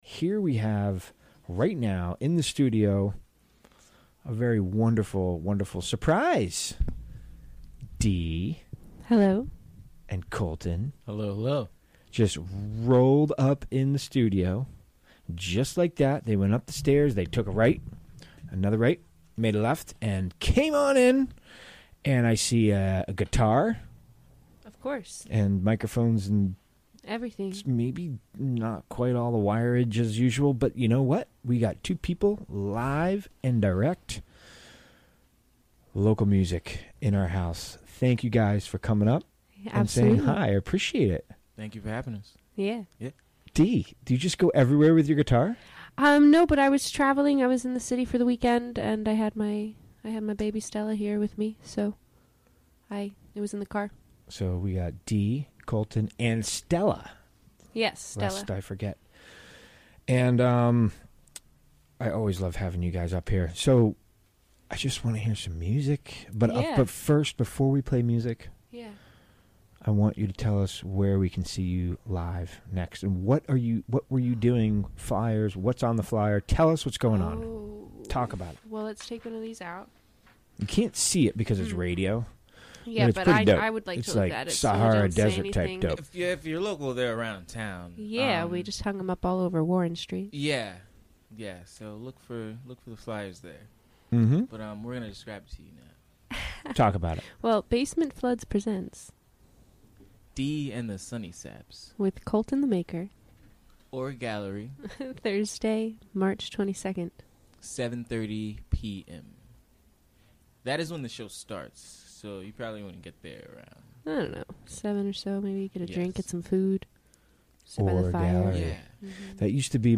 Recorded live on the WGXC Afternoon show on March 19, 2018.